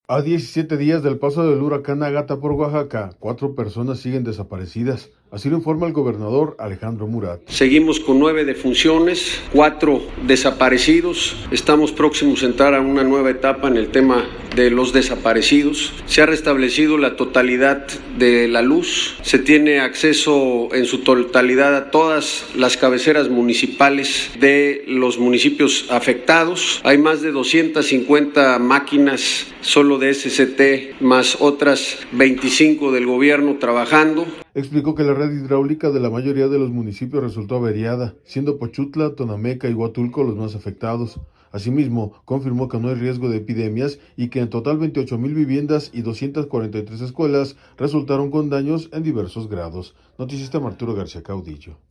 A 17 días del paso del huracán “Agatha” por Oaxaca, cuatro personas siguen desaparecidas, así lo informa el gobernador Alejandro Murat.